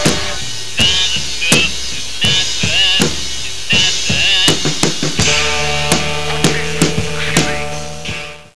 The three rivet cymbal